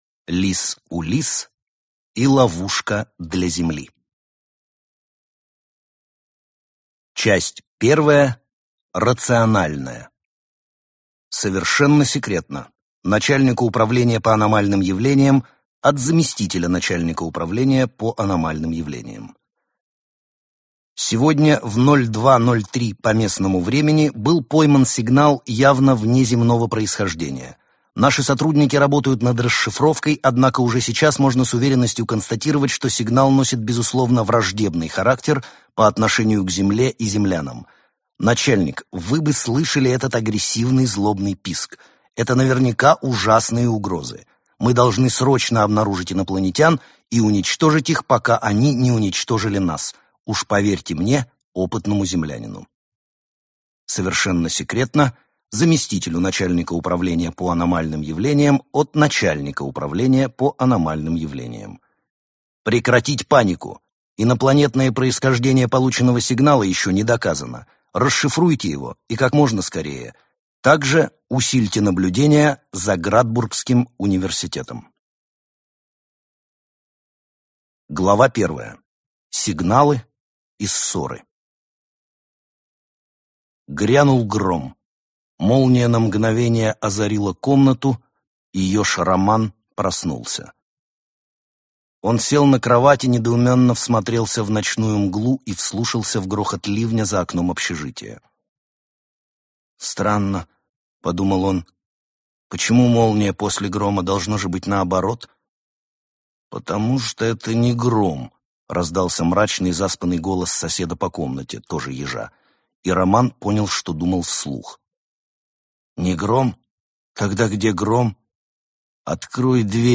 Аудиокнига Лис Улисс и ловушка для Земли | Библиотека аудиокниг